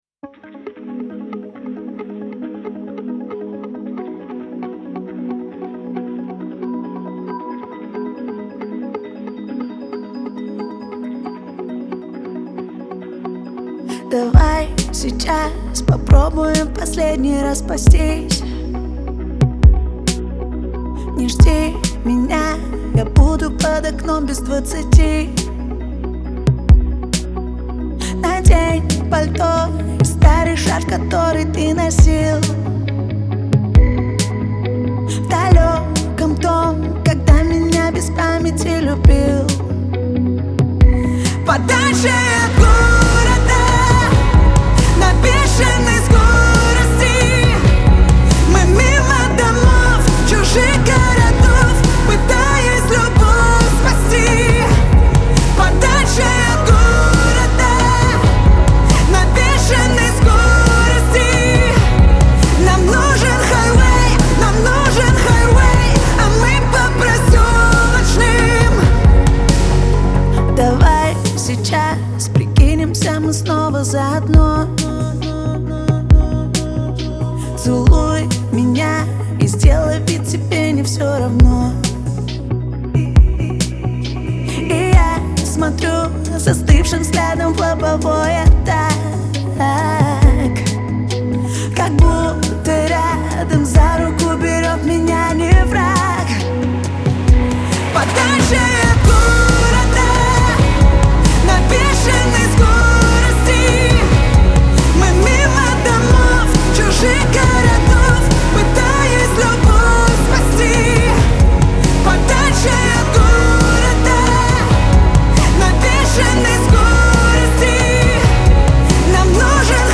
яркая поп-песня
Звучание песни отличается мелодичностью и легкостью